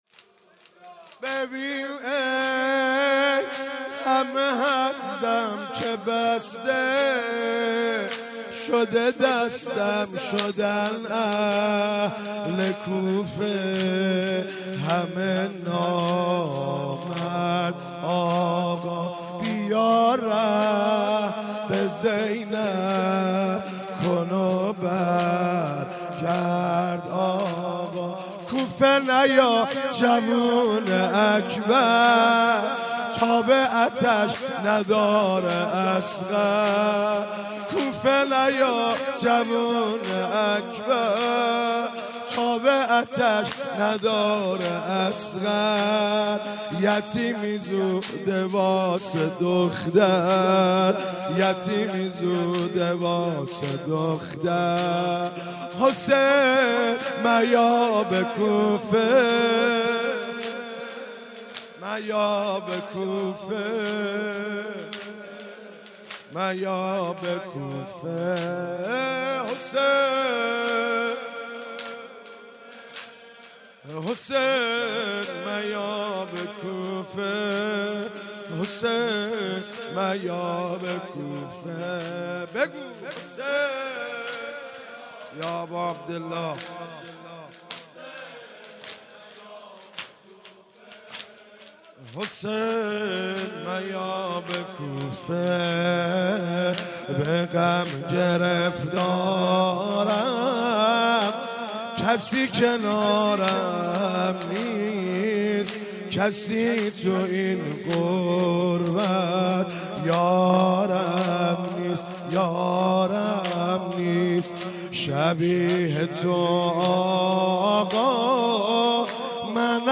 3- ببین ای همه هستم - زمینه